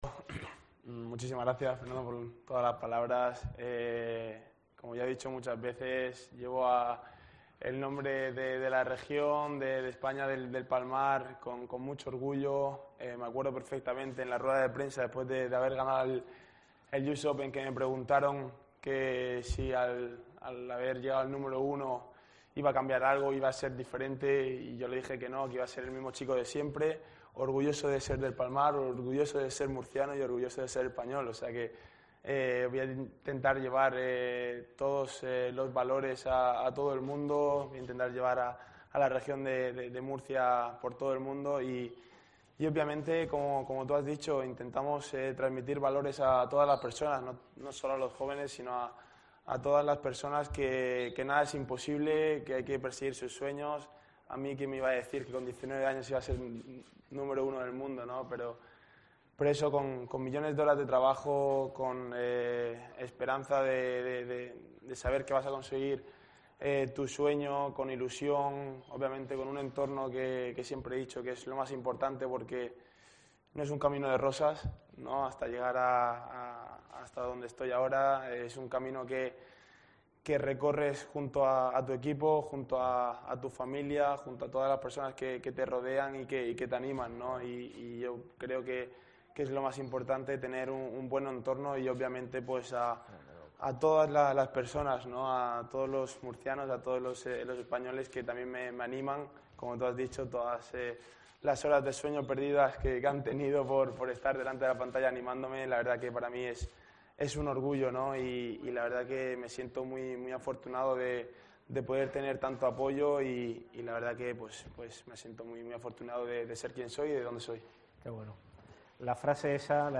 El tenista de El Palmar ha hecho estas declaraciones en una rueda de prensa que ha ofrecido en Murcia tras ser recibido por el presidente del Gobierno regional, Fernando López Miras, en el Palacio de San Esteban --sede del Ejecutivo autonómico--.